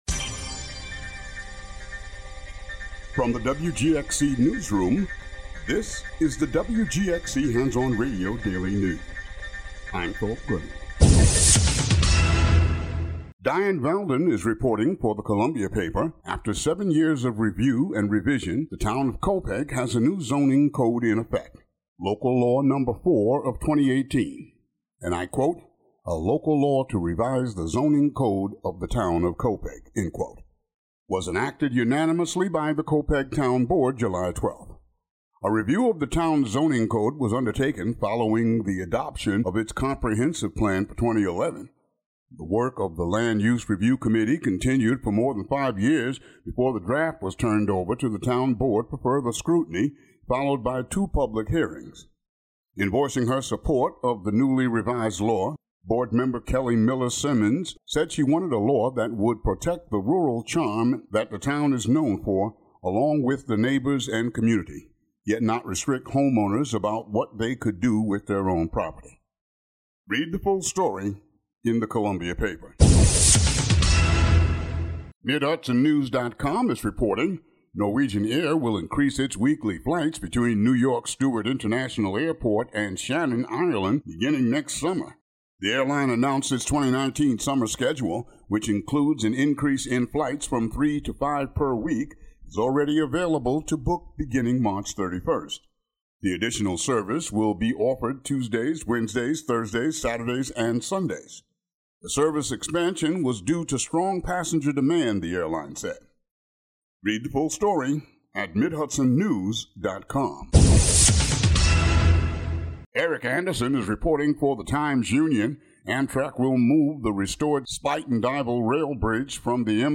Daily headlines for WGXC.